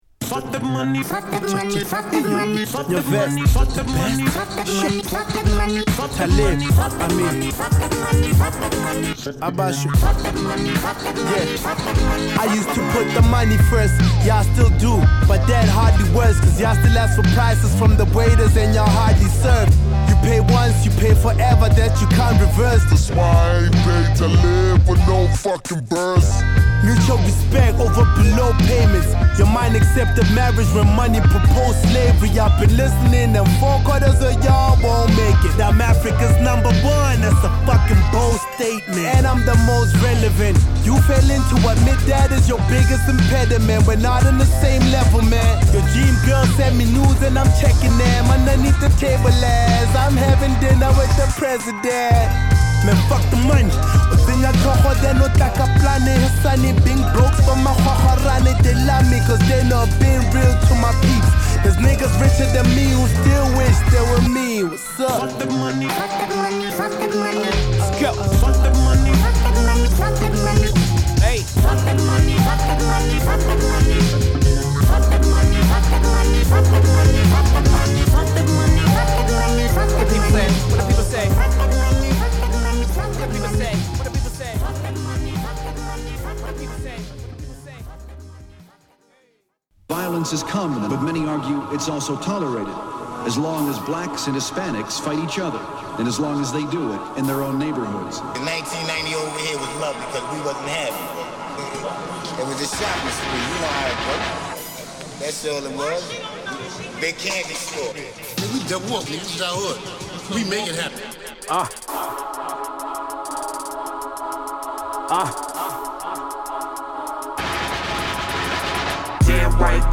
バラエティに富んだ仕上がり！
＊試聴はB1→A3→C1→C3です。